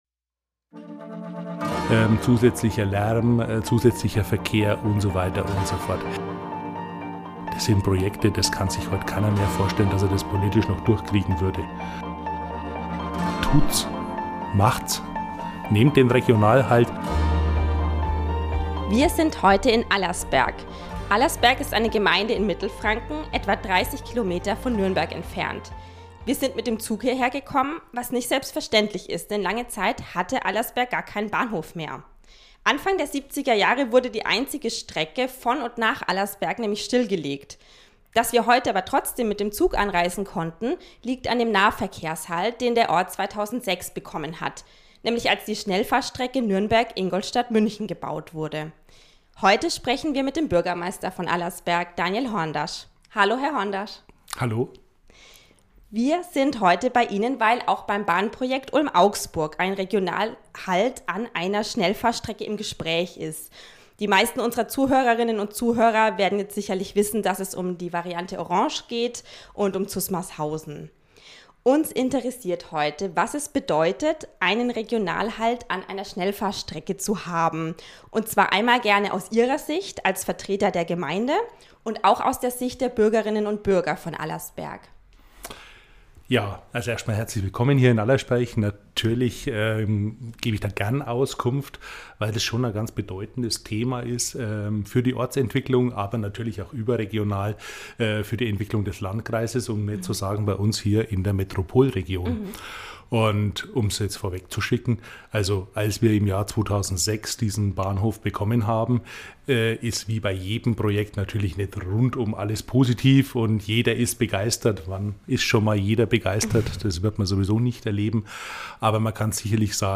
Ein Gespräch über den Lärm von Auto- und Eisenbahn, umstrittene Großprojekte und ob die Menschen den Zug heute überhaupt nutzen.